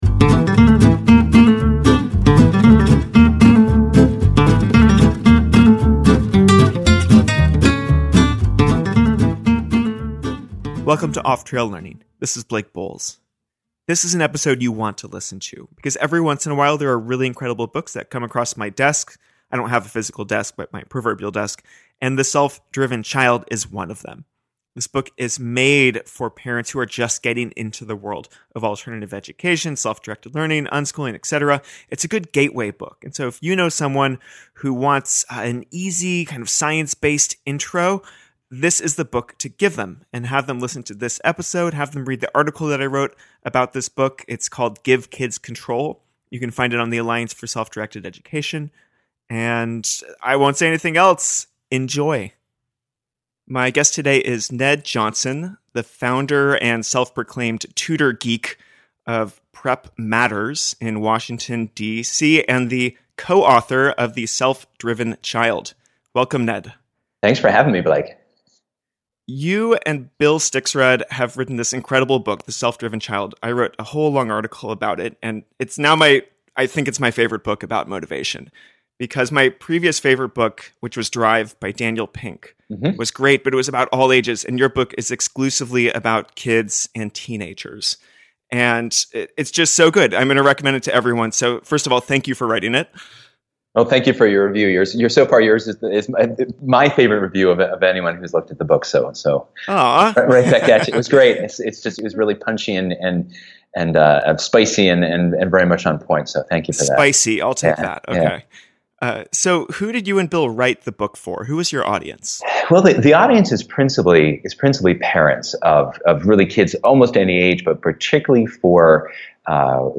In the interview